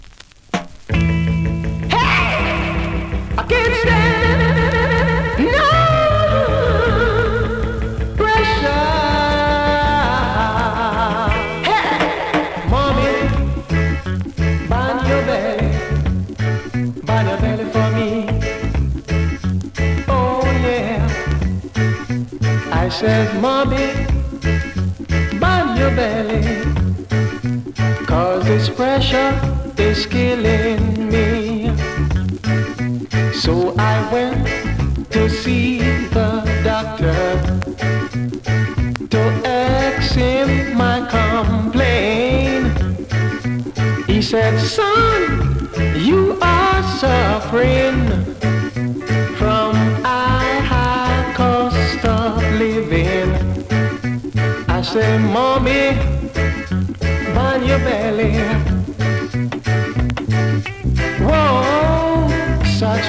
ホーム > 2020 NEW IN!!SKA〜REGGAE!!
スリキズ、ノイズ比較的少なめで